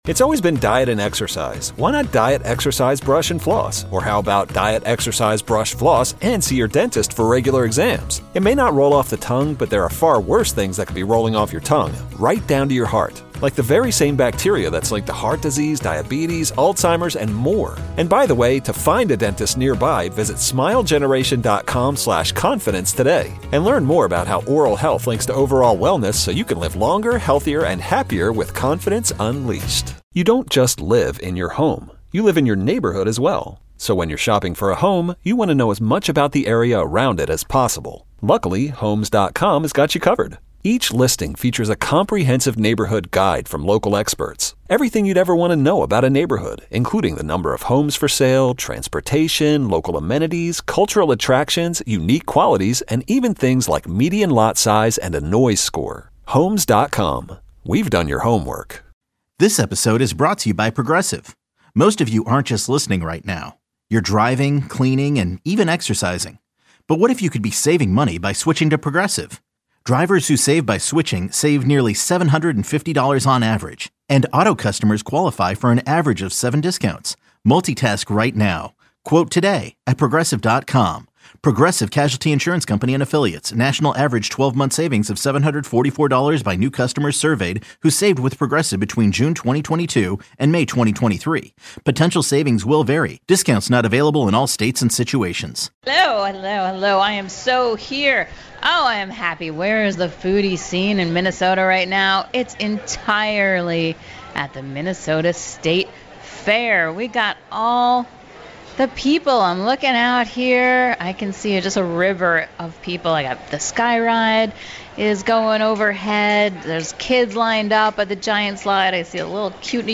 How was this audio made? at our Fair booth